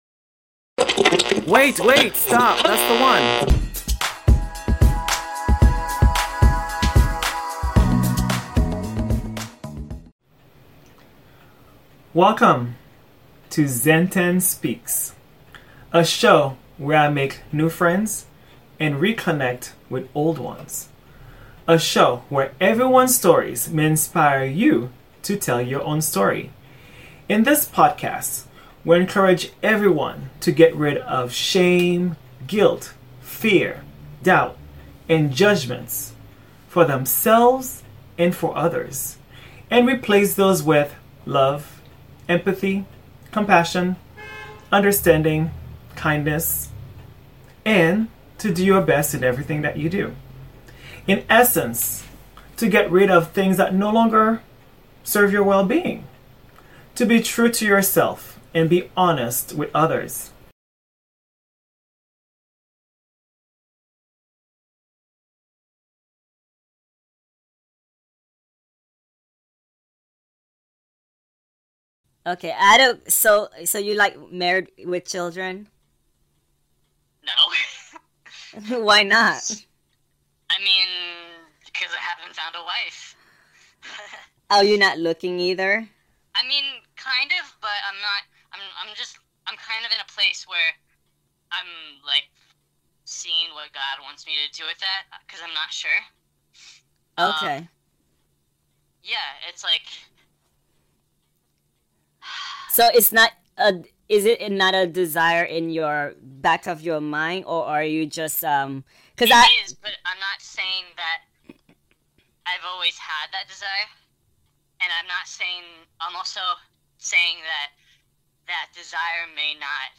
Voice is also altered. He caller talks about his journey, his love for Jesus Christ and his determination to reject "sin" of homosexuality.